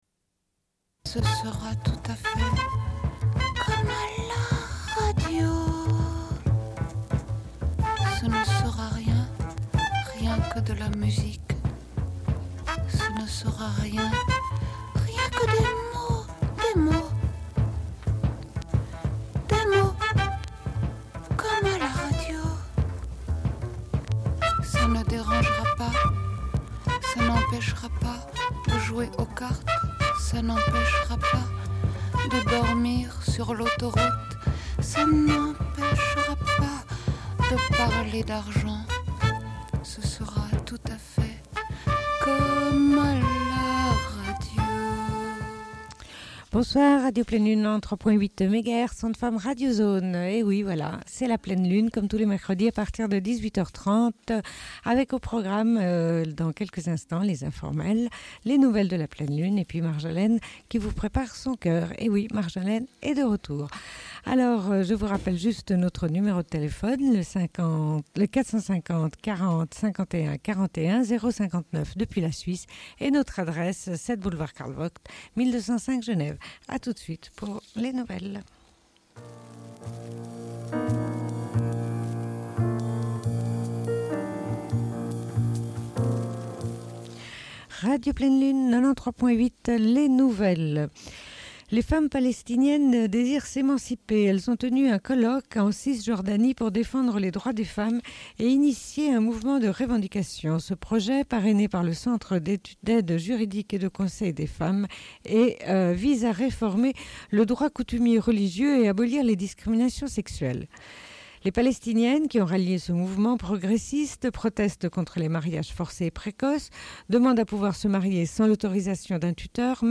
Bulletin d'information de Radio Pleine Lune du 29.04.1998 - Archives contestataires
Une cassette audio, face B